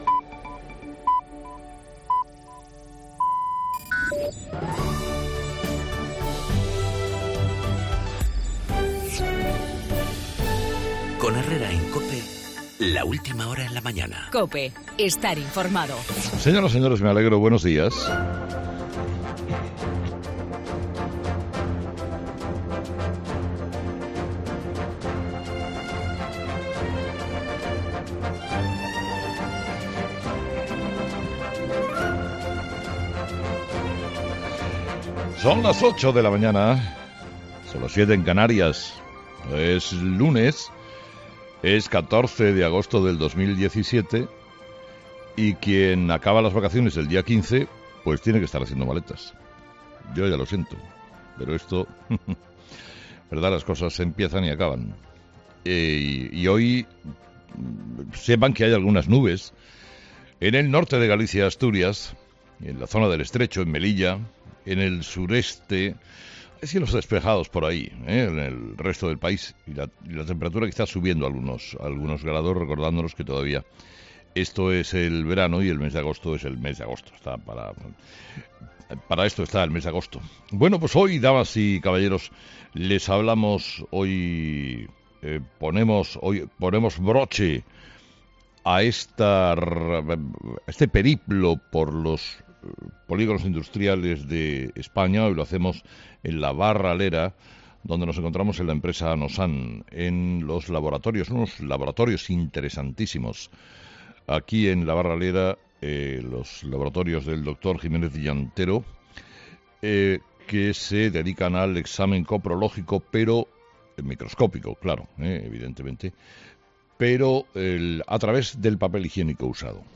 AUDIO: La huelga de los trabajadores de seguridad en el Aeropuerto del Prat, en el monólogo de Carlos Herrera a las 8 de la mañana.